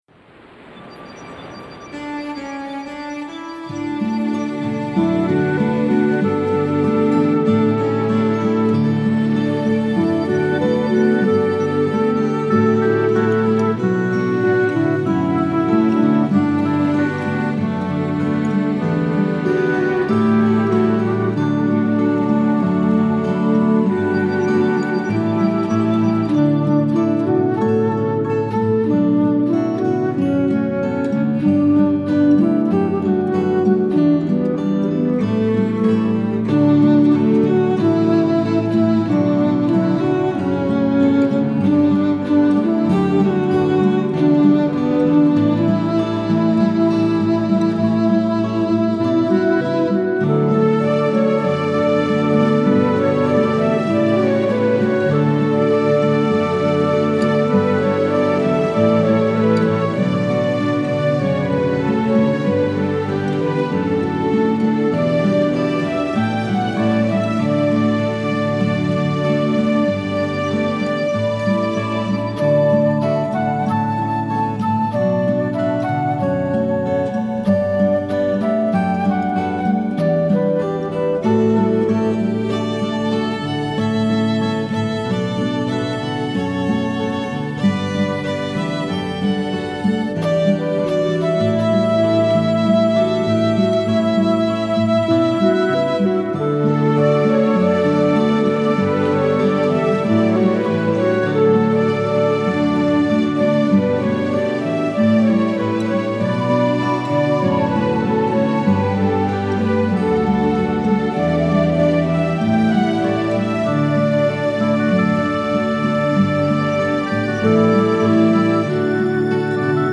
Initially, I worked with an arranger to create these lush arrangements.
2-crystal-oceans-instrumental.mp3